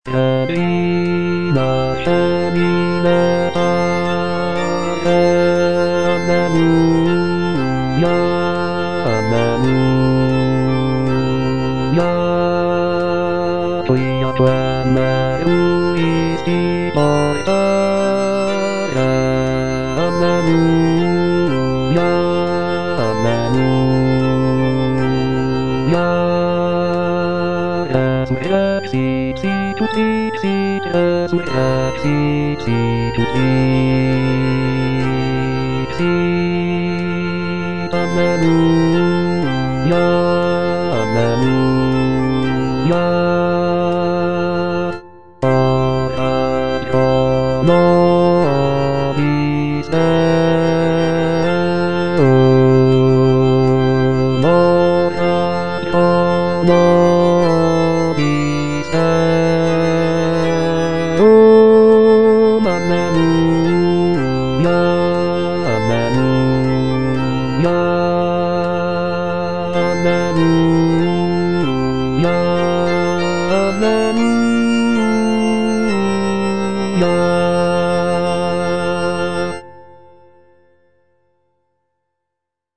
Répétition SATB4 par voix
Basse